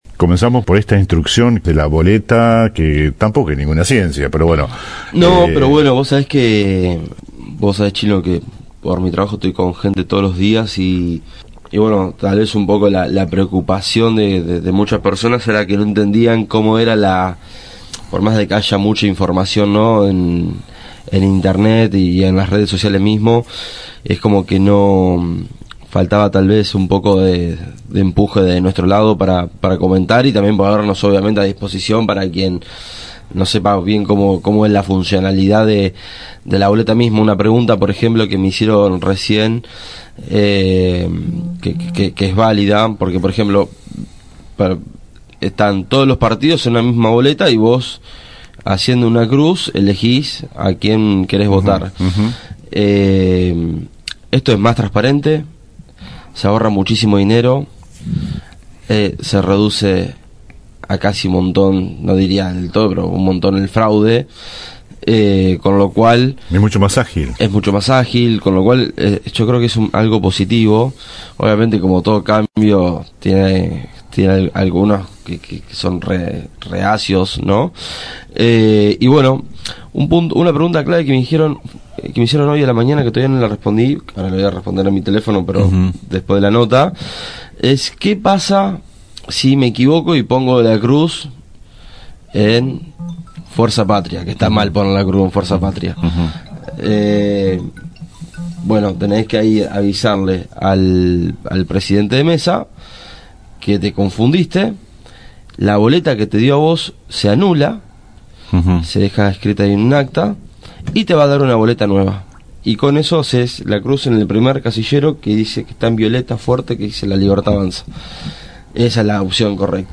Por el tema de la mecánica del voto a través de la Boleta Única de Papel, Invitamos al piso de la radio al máximo referente de la LLA el electo concejal Bernardo Lemma.